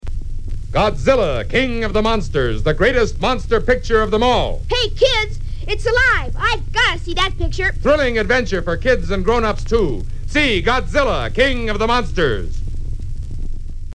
Oddly enough, Godzilla’s characteristic roar is not heard in these spots.
So, here they are…as listeners would have heard them back in 1956!
Godzilla King of the Monsters Radio Spots for 12, 50, and 100 seconds versions.